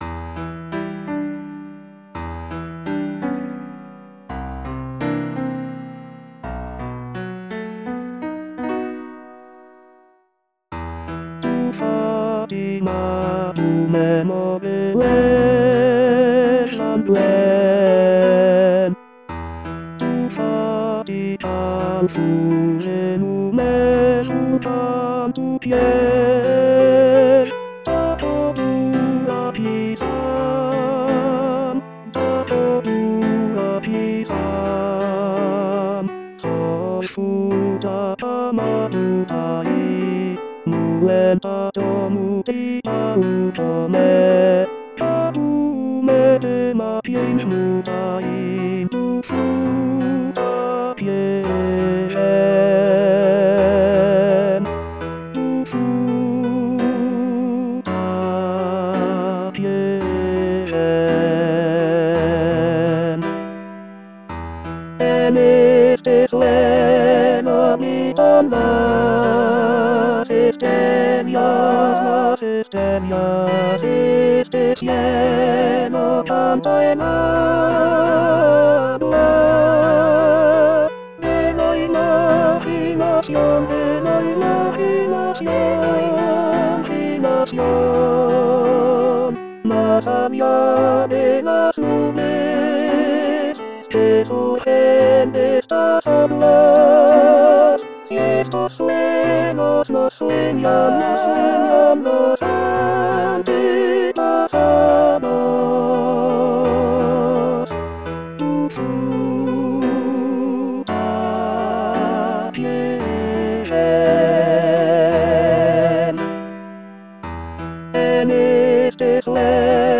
Tenor Tenor 1